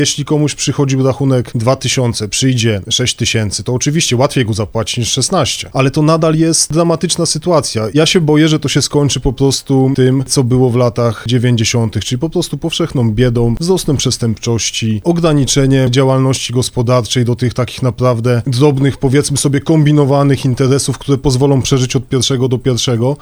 To, że będziemy płacić dwa razy więcej a nie osiem, nie jest żadną receptą, mówi wiceprezydent Mateusz Tyczyński: